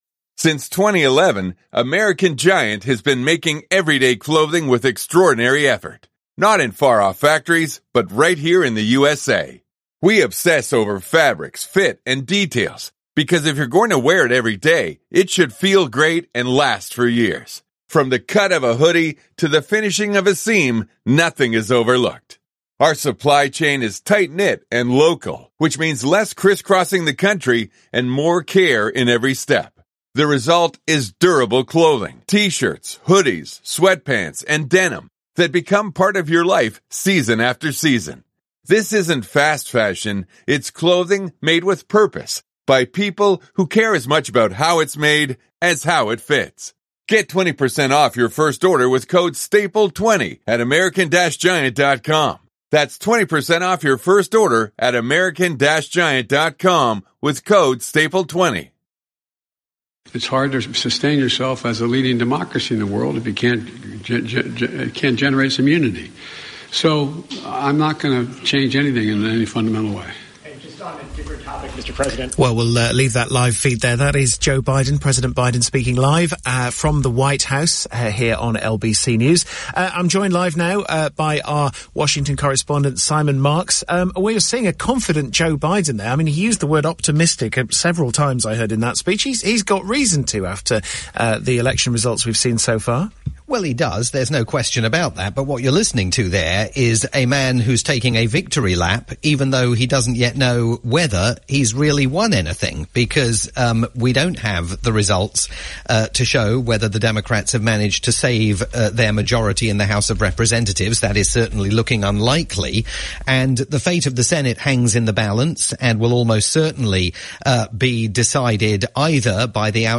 live analysis